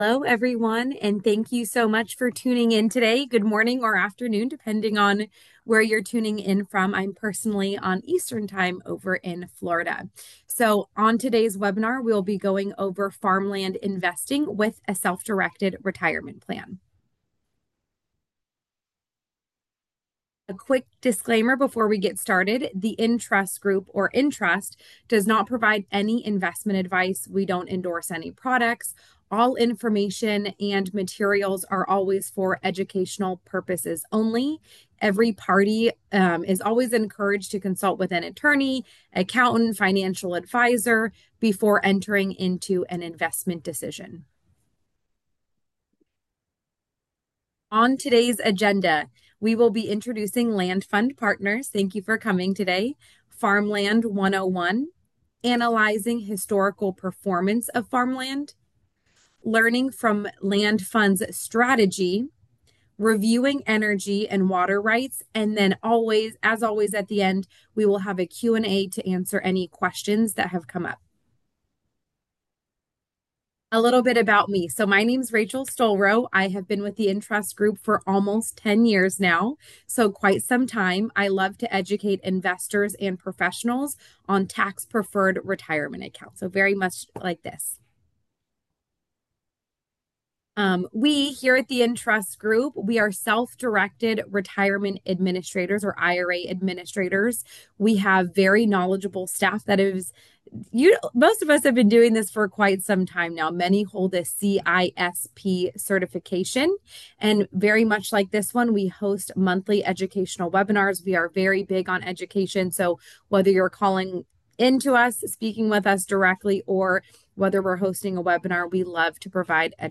In this educational training, we'll provide an introduction to farmland investing.